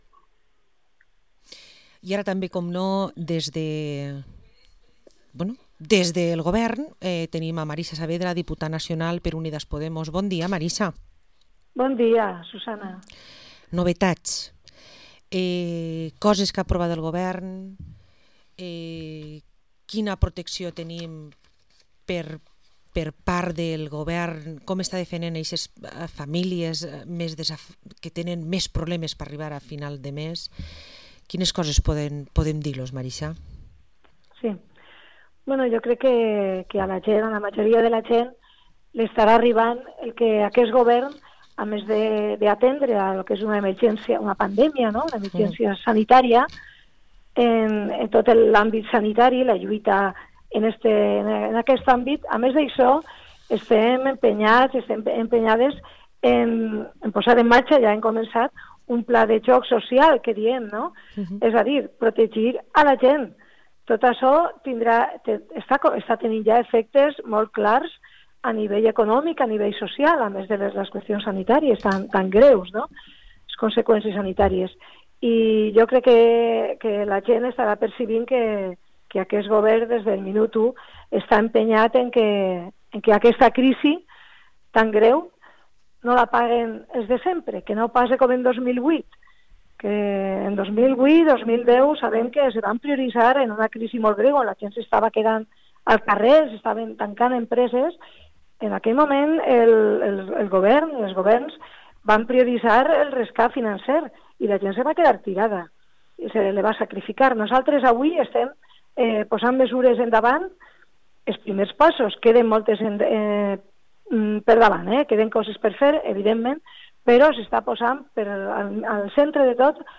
Entrevista a Marisa Saavedra, diputada nacional de Unidaspodemos